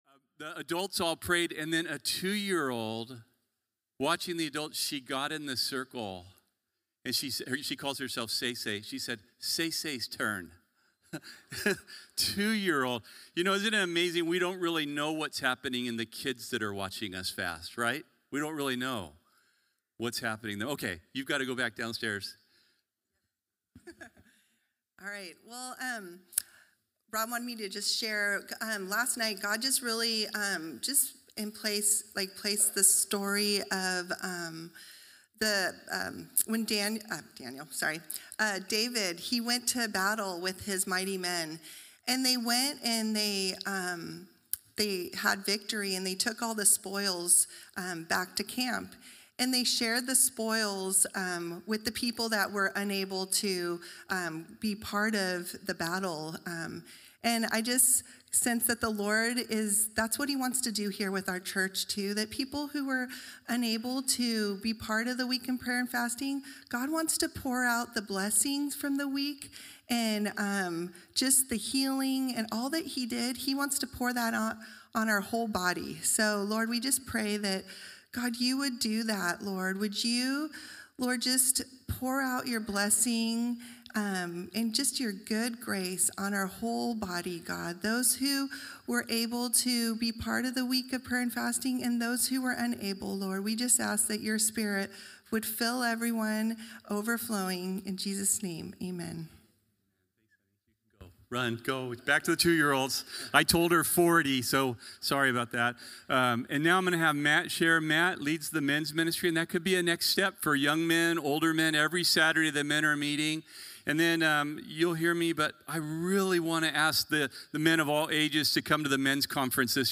The Bible study given at Calvary Chapel Corvallis on Sunday, February 1, 2026..